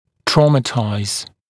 [‘trɔːmətaɪz][‘тро:мэтайз]травмировать, нанести травму